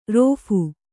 ♪ rōphu